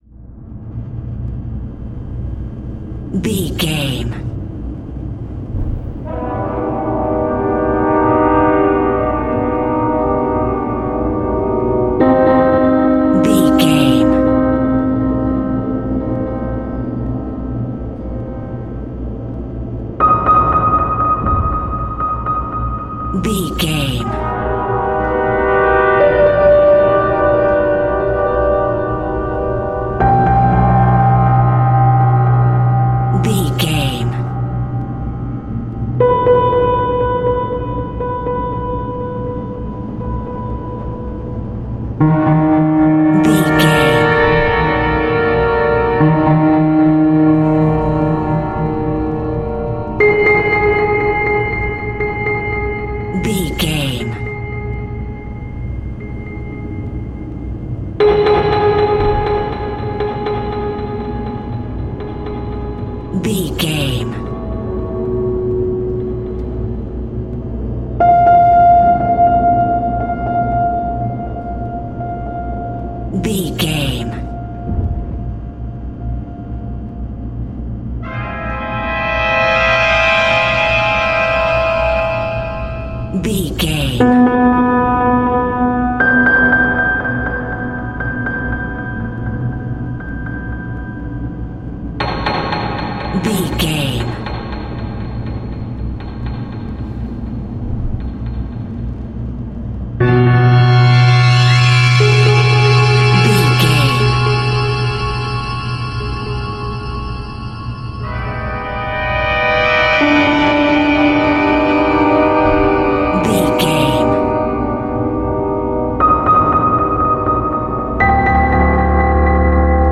Thriller
Aeolian/Minor
Slow
ominous
dark
haunting
eerie
strings
piano
synth
ambience
pads